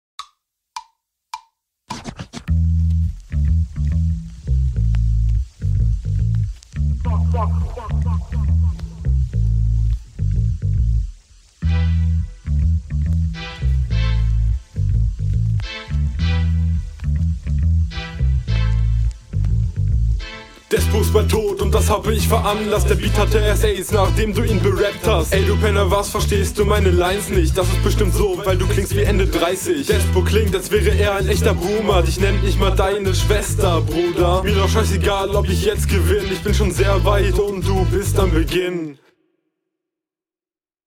20 Sekunden Intro, 40 Insgesamt..
schonwieder so ne kurze runde. funky beat yeah.. puh alter, ich weiss nicht was ich …